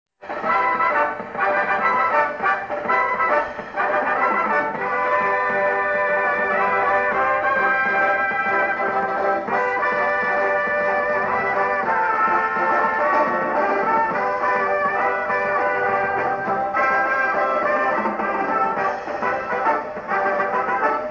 * (Before the age of cell phones I brought my little micro-cassette recorder and made a crude but cool recording of the circus from inside the band I played with during the next to last show on Saturday.